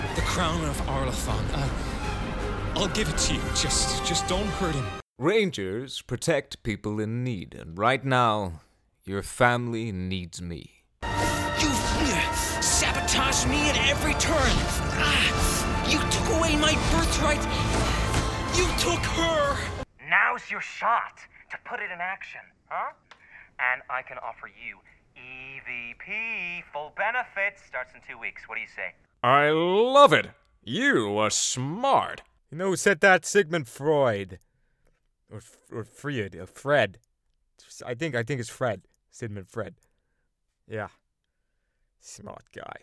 standard us | character
Standard_American_mixed_samples2.mp3